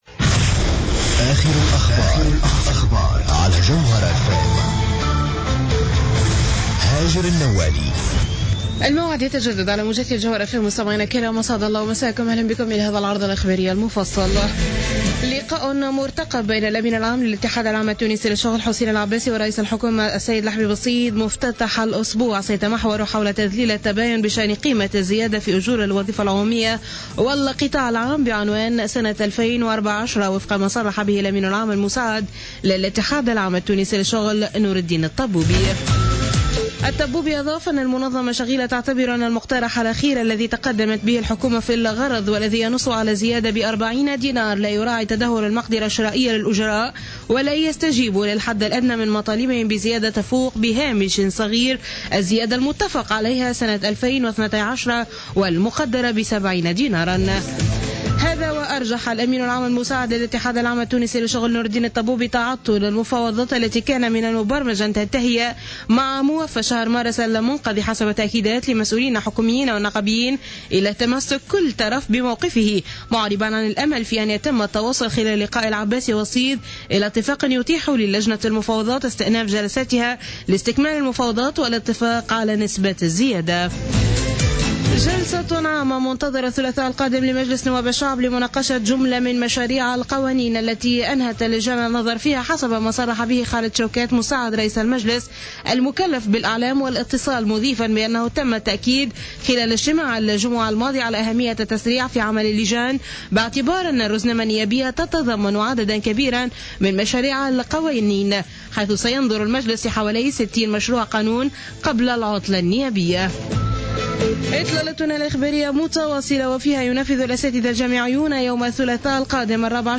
2015 نشرة أخبار منتصف الليل ليوم الاثنين 13 أفريل